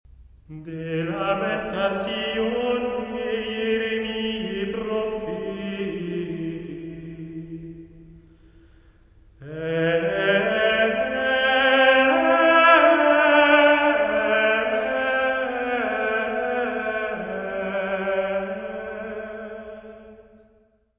Chant Grégorien : Liturgie du Vendredi Saint
: stereo; 12 cm
Área:  Música Clássica
Leçon de Ténèbres: De Lamentatione Jeremiae Prophetae, mode de ré.